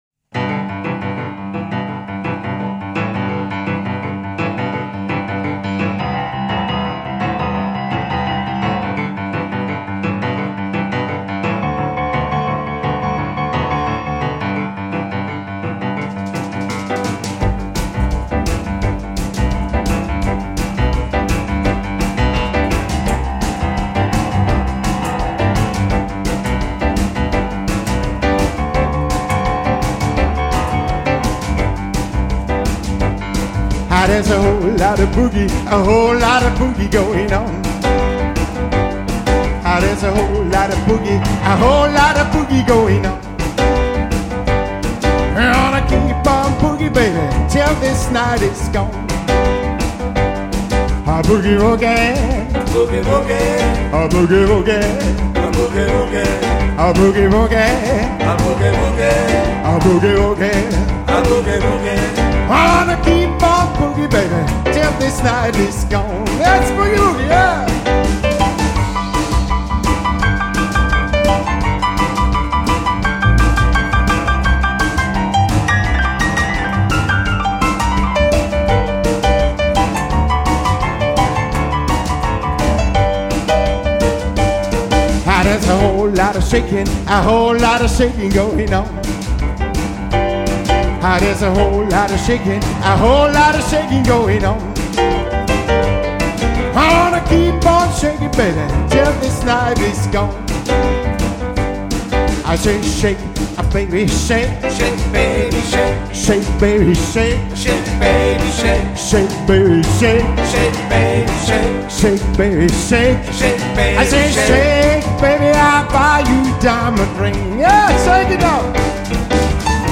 • Includes pure unadulterated boogie woogie and blues.
• I play on a Steinway and Sons "B" grand piano
p/voc/d/b